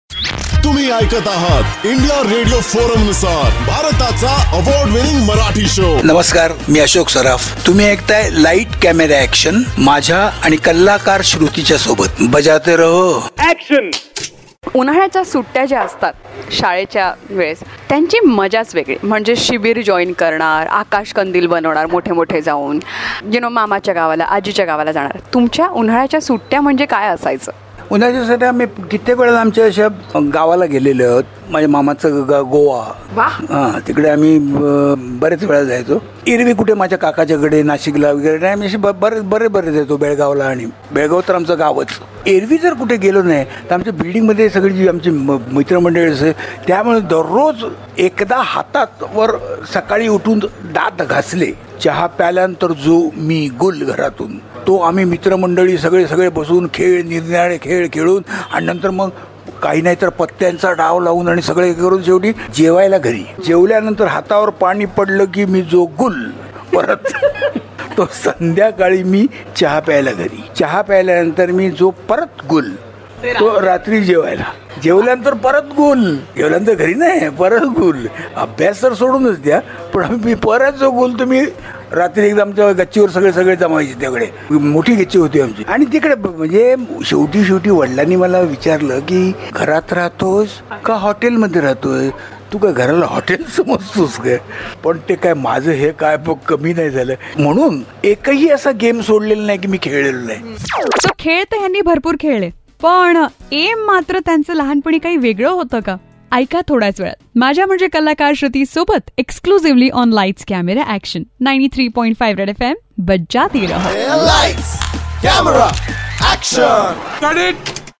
VETRAN ACTOR ASHOK SARAF TALKS ABOUT HIS SUMMER VACATIONS AS A KID